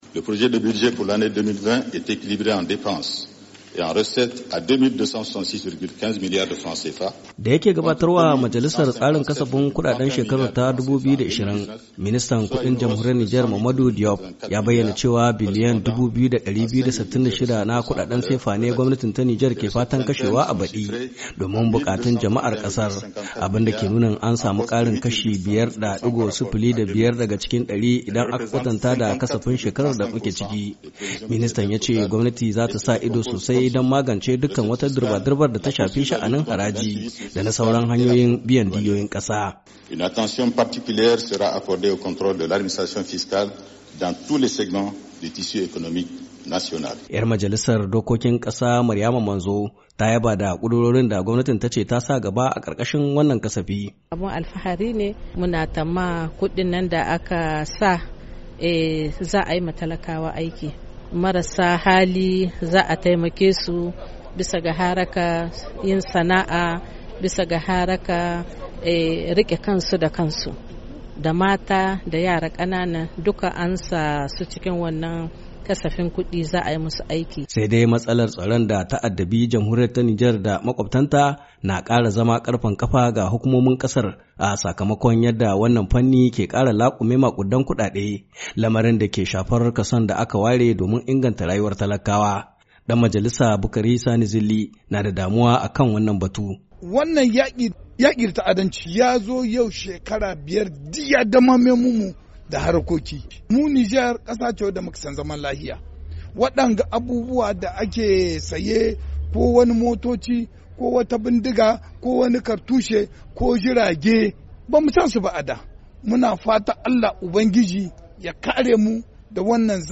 Ga cikakken rahoto